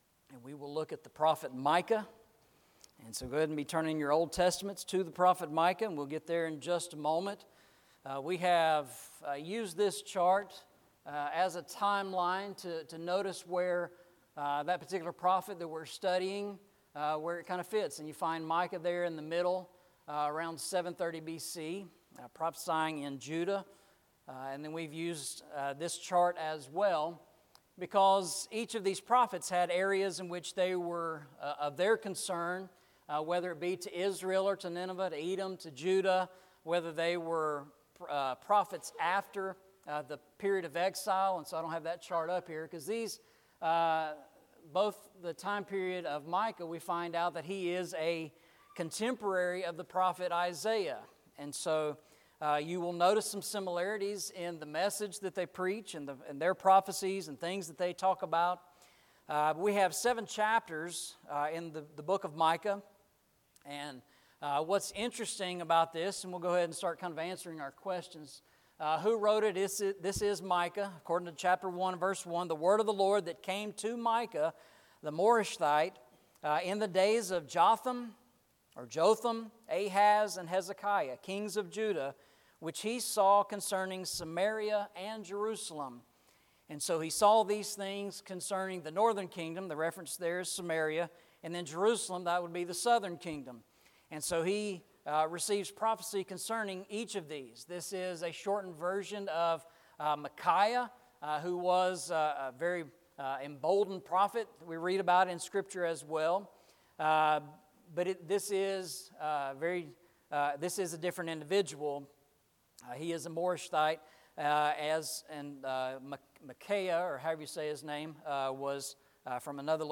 Series: Eastside Sermons Passage: Micah 7:18 Service Type: Sunday Evening « Christ